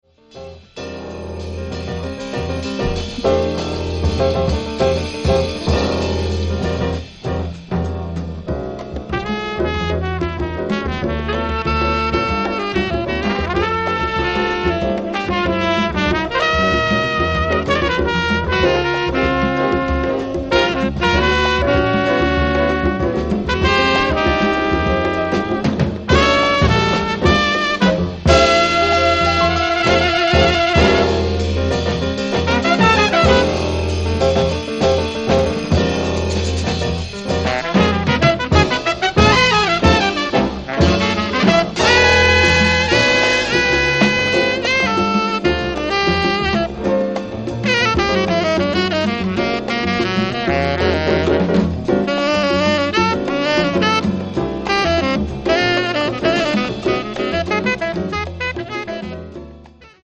Jazz Funk